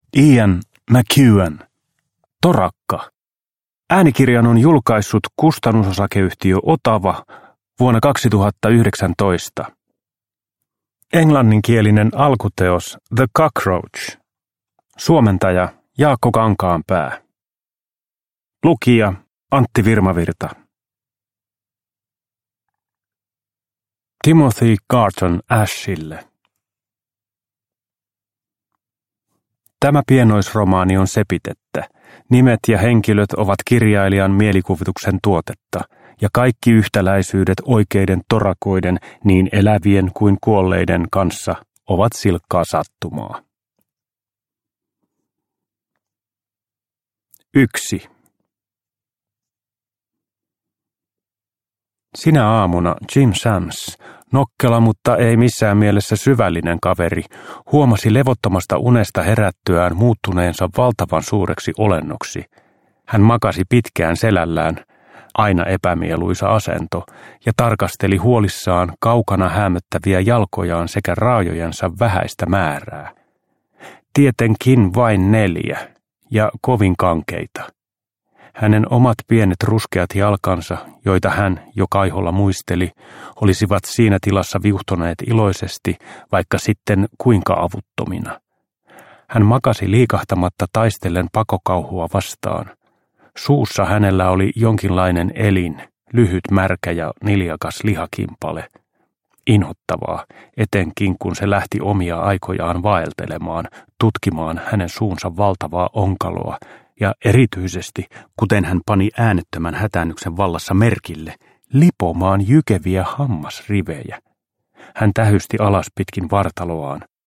Torakka – Ljudbok